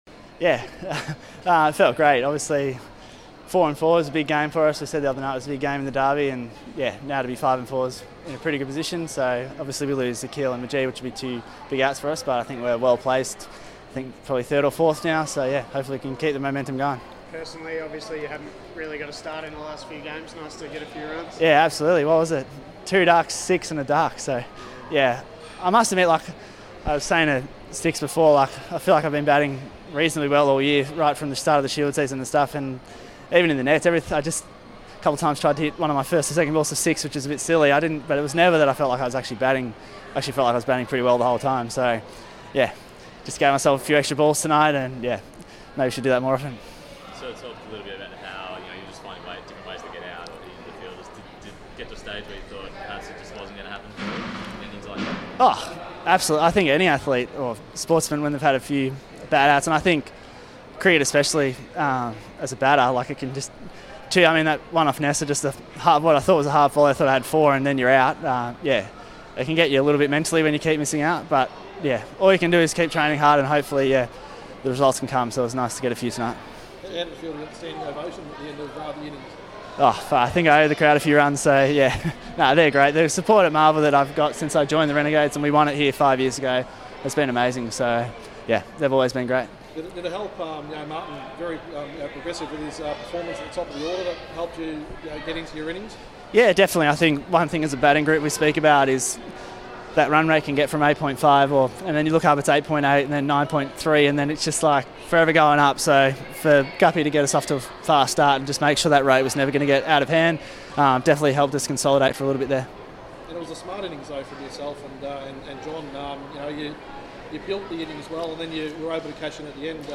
Sam Harper spoke to media after his 89 from 48 balls to lead the Renegades to a 6-wicket win over the Hurricanes.